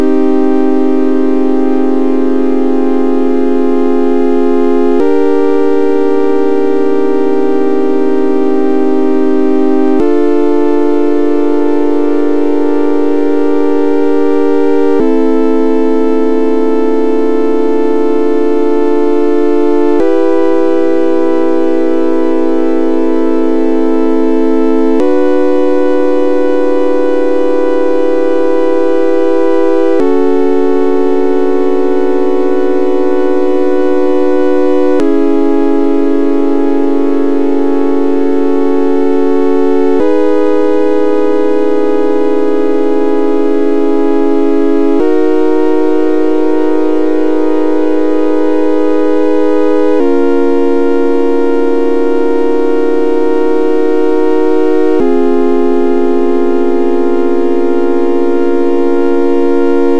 voici donc un cercle des quintes continu en
progression mineure.